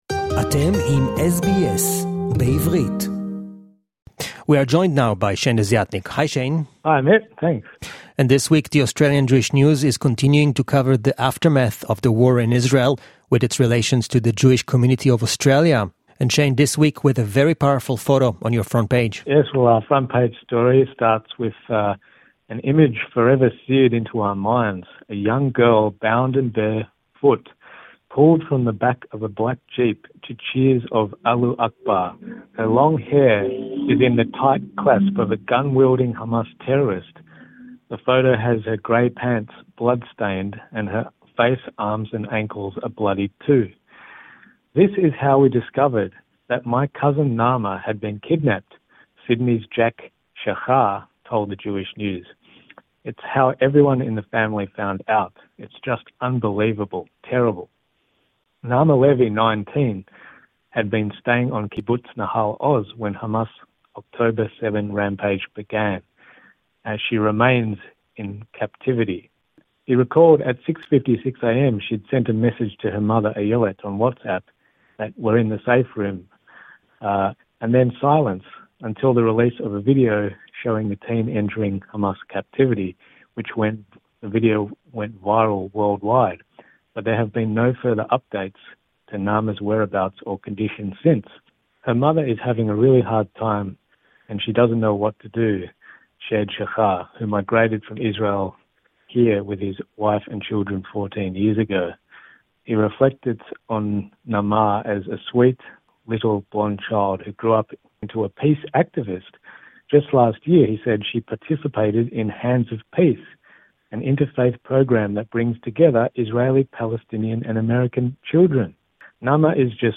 weekly report for SBS Hebrew.